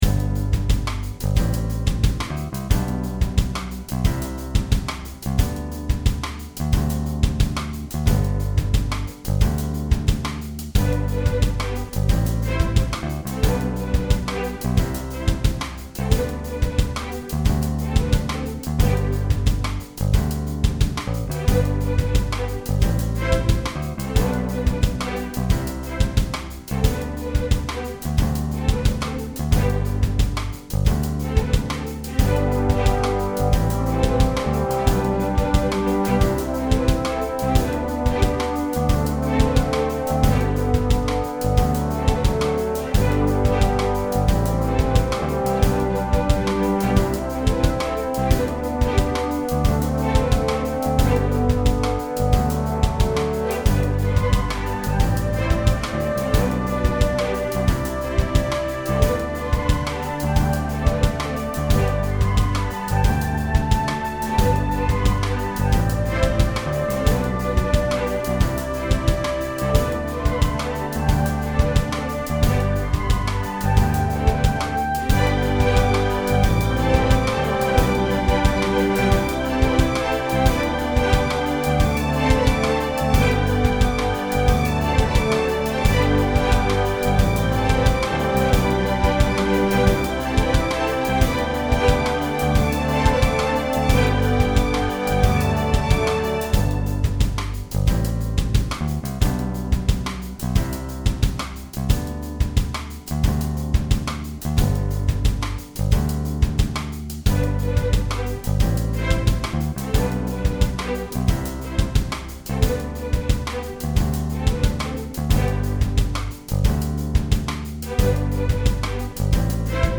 These files are for you to hear what the various accompanying forces sound like and for your practice and memorization.
Wahamba Nathi (Traditional African Song)